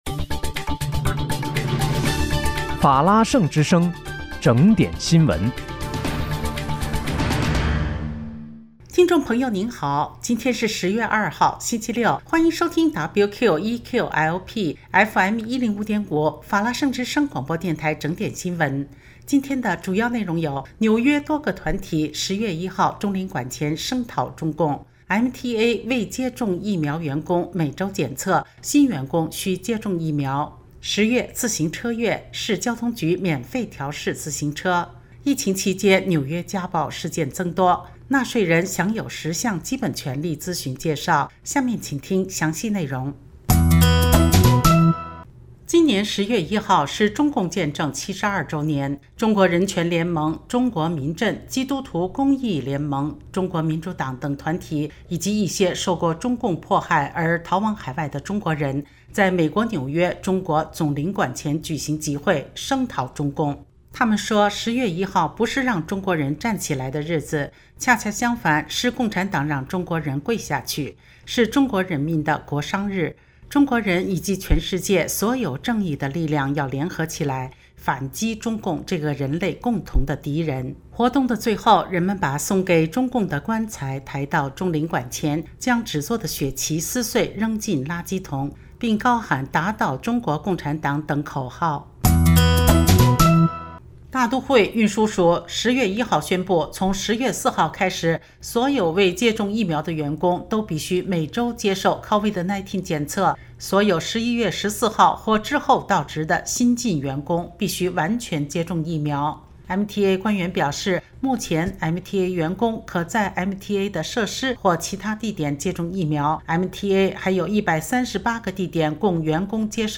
10月2日（星期六）纽约整点新闻
听众朋友您好！今天是10月2号，星期六，欢迎收听WQEQ-LP FM105.5法拉盛之声广播电台整点新闻。